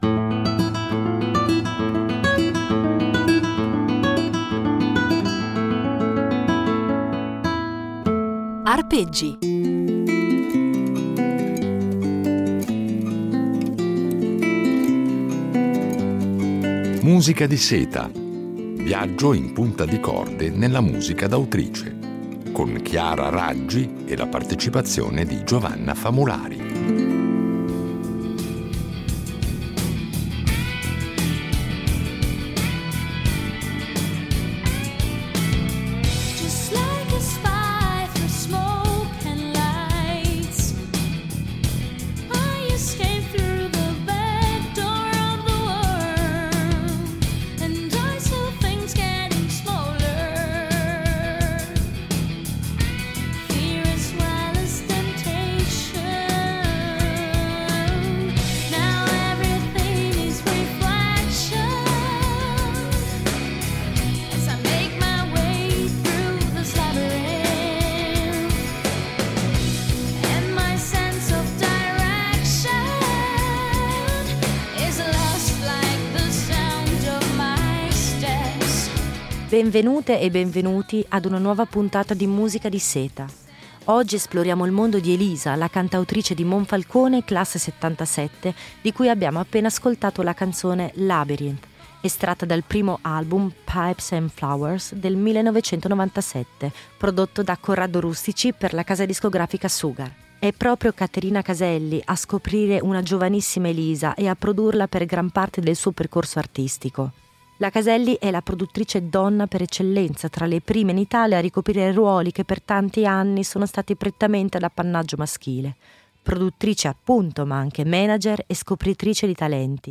Una serie di Arpeggi impreziosita dalle riletture originali di un duo, ancora inedito, con la violoncellista